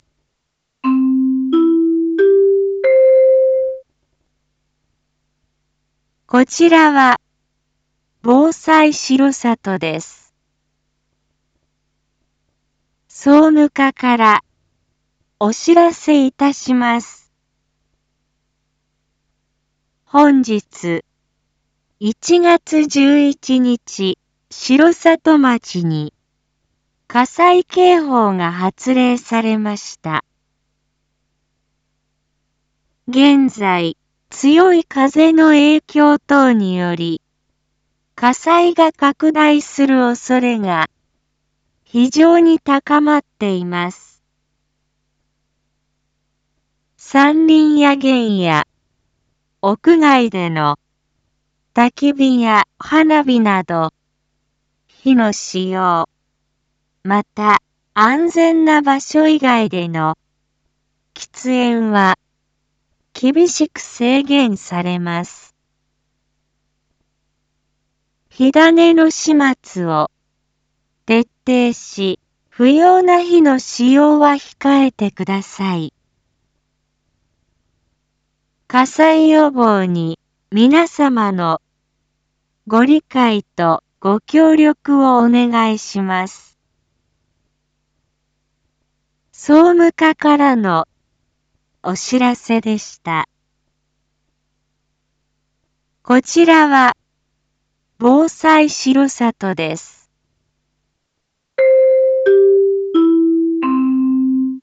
Back Home 一般放送情報 音声放送 再生 一般放送情報 登録日時：2026-01-11 12:31:46 タイトル：R8.1.11火災 警報発令 インフォメーション：本日、1月11日、城里町に火災警報が発令されました。